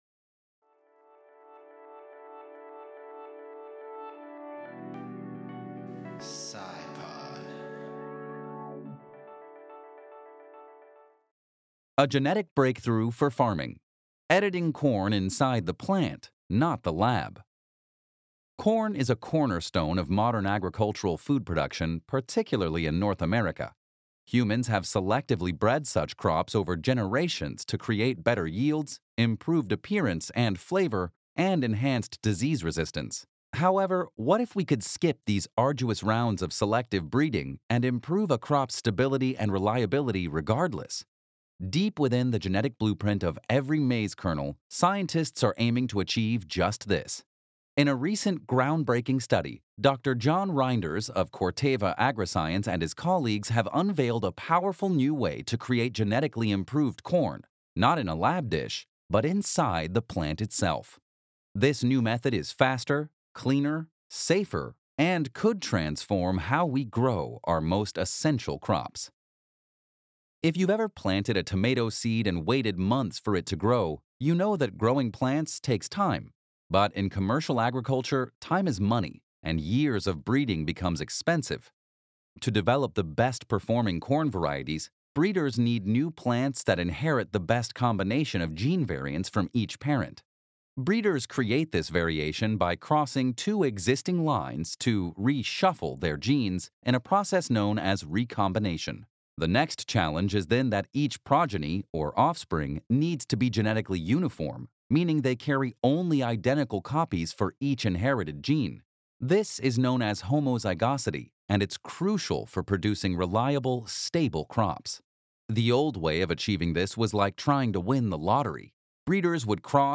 In this episode of SciPod Radio we talk with Dave Goulson about his research on bumblebees. We cover why they’re so important to our existence, how to get more involved with bees if your a keen gardener, and even how you can help if you’re not green-fingered or you don’t have a garden…